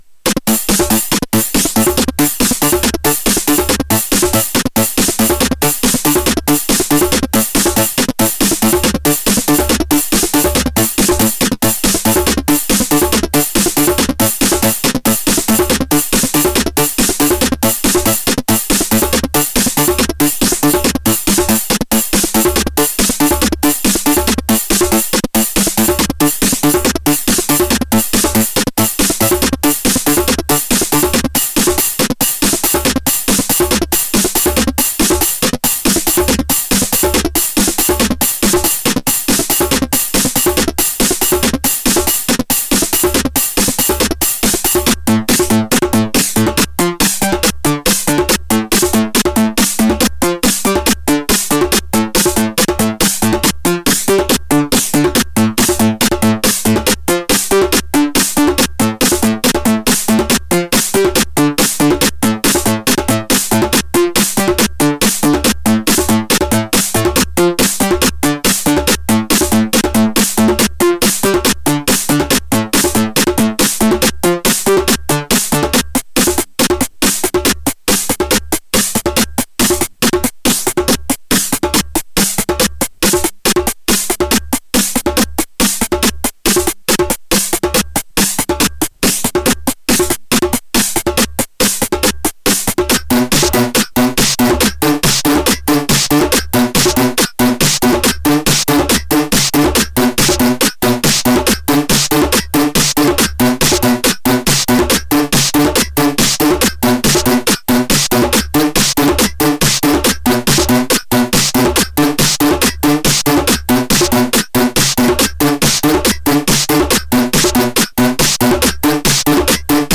flanger-effect.mp3